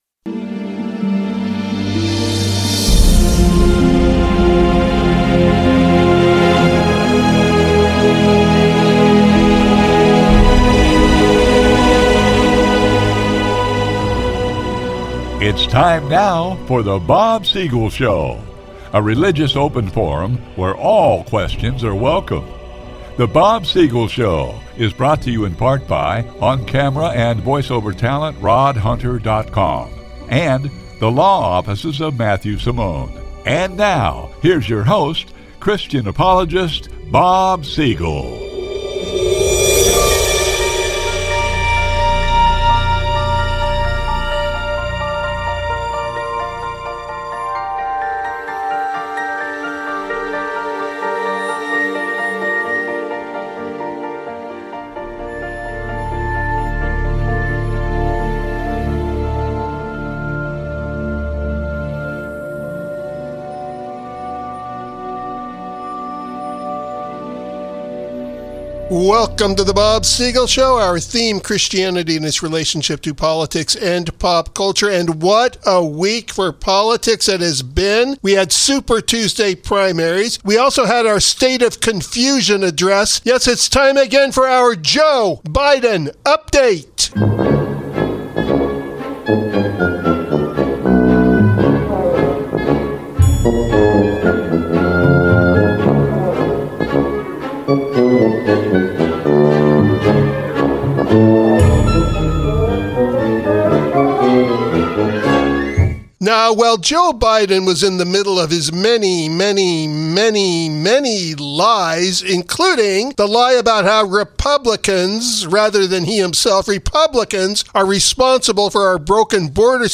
originally recorded from a live broadcast while the awards were going on in real time.